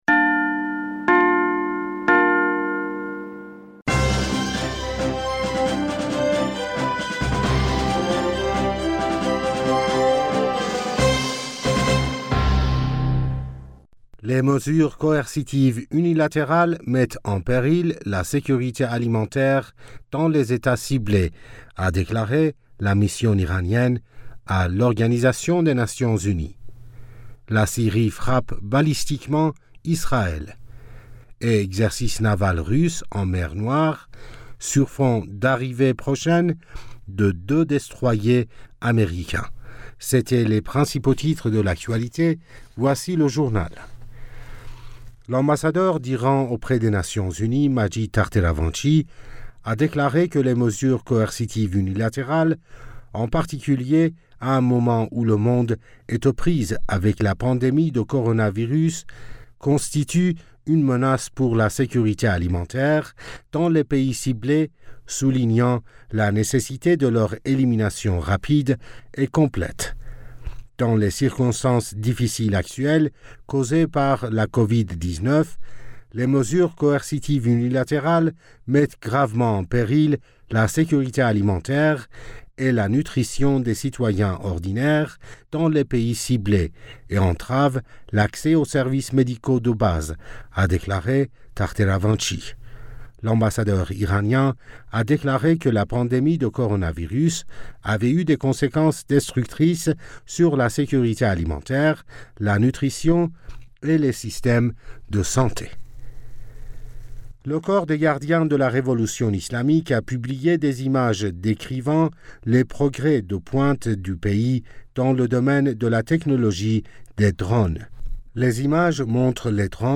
Bulletin d'information du 22 Avril 2021